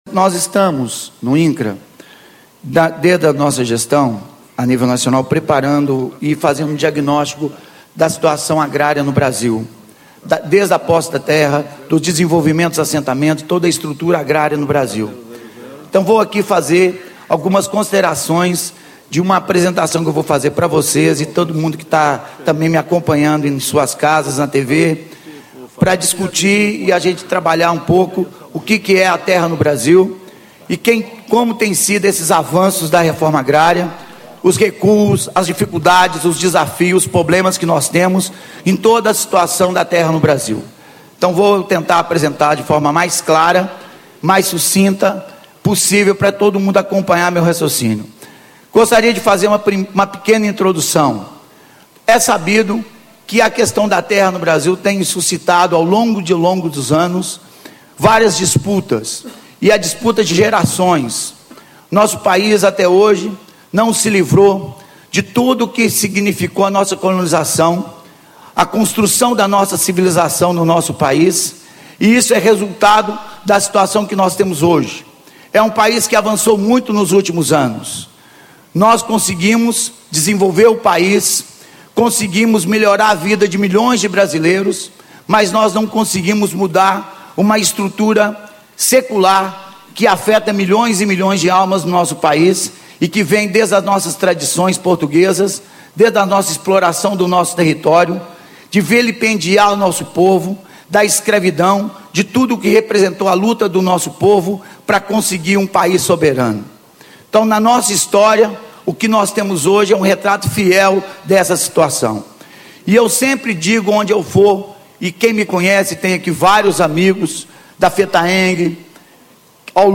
Carlos Calazans, Superintendente Regional do Instituto Nacional de Colonização e Reforma Agrária - Incra. Painel: Acesso à Terra e Regularização Fundiária
Ciclo de Debates Agricultura Familiar e Desenvolvimento Sustentável
Discursos e Palestras